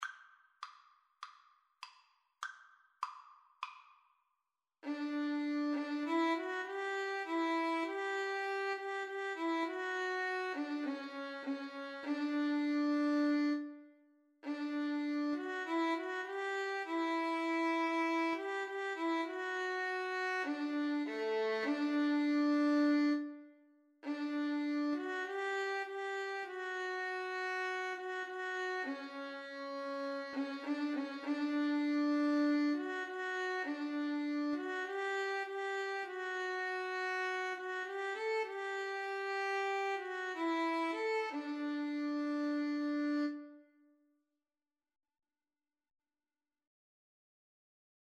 Violin 1Violin 2
4/4 (View more 4/4 Music)